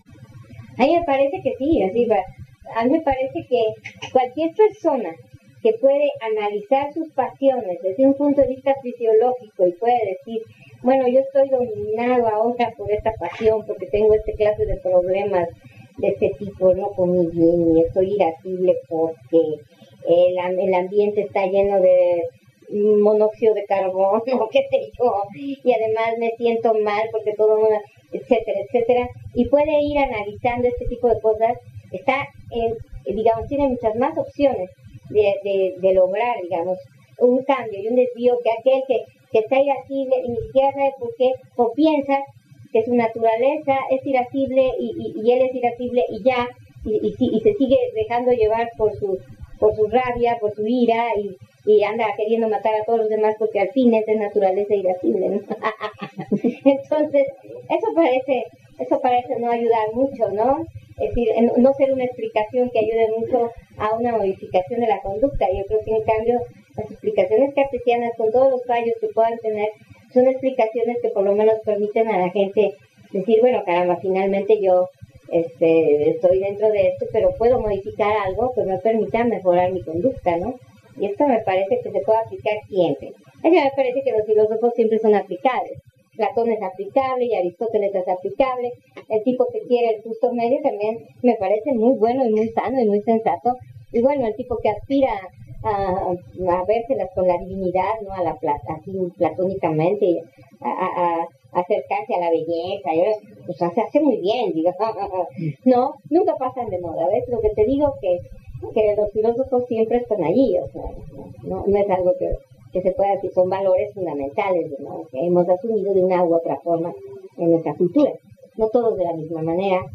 Entrevista: La filosofia cartesiana: una ancla a la razon